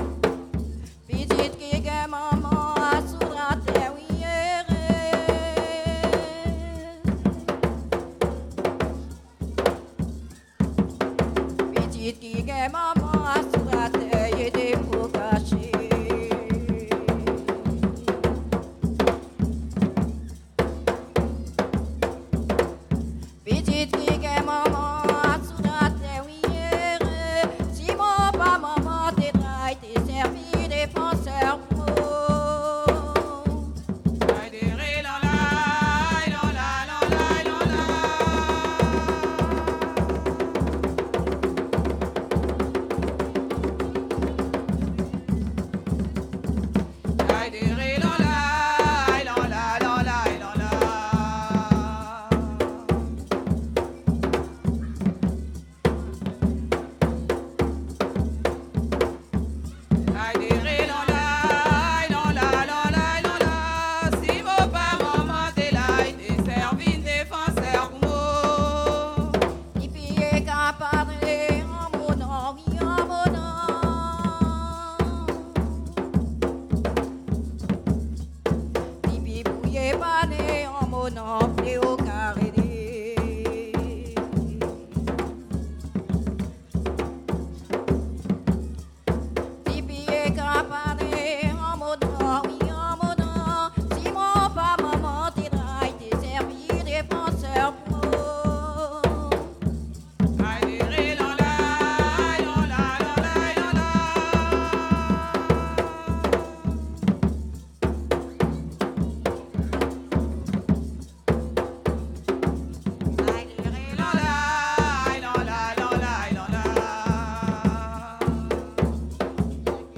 Soirée Mémorial
danse : léròl (créole)
Pièce musicale inédite